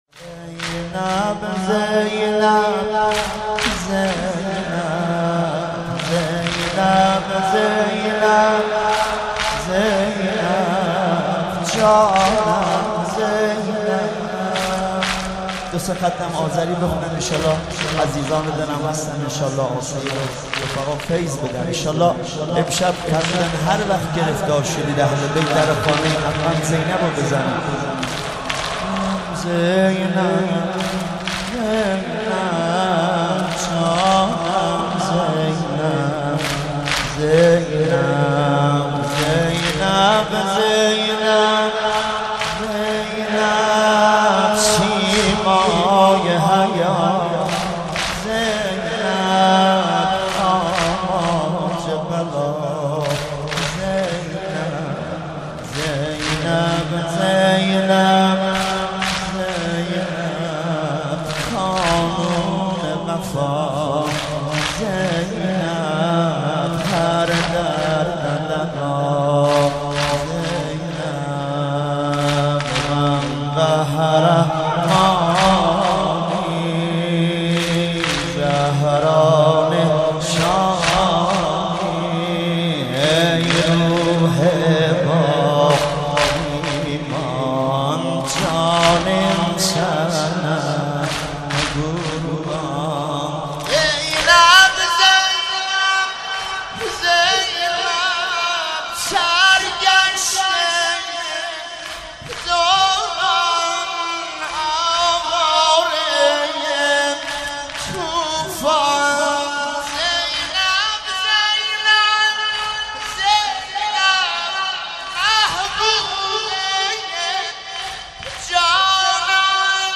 مناسبت : شهادت امام رضا علیه‌السلام